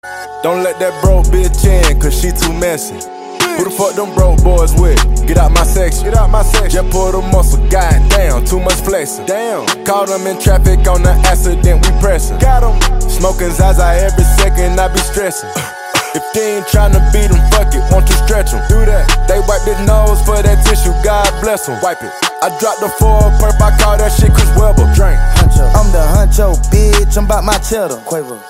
Catégorie: Rap - Hip Hop